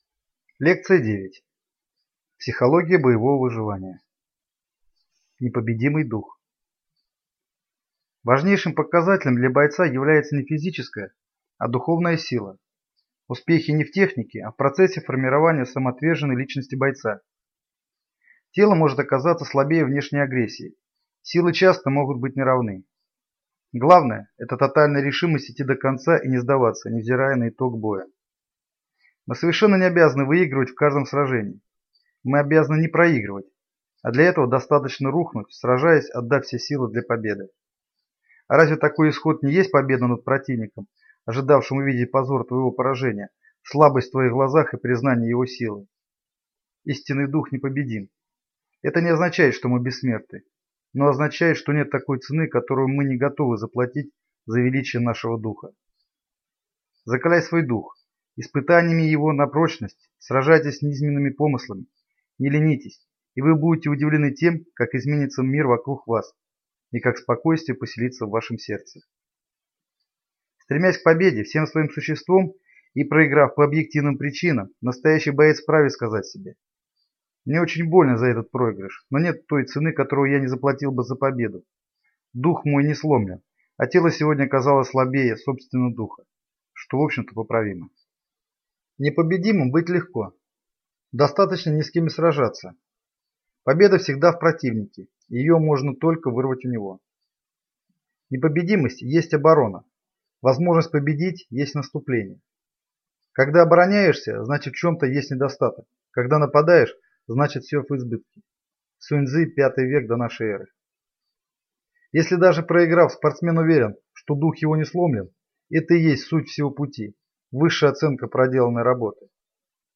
Аудиокнига Лекция 9. Психология боевого выживания. Непобедимый дух | Библиотека аудиокниг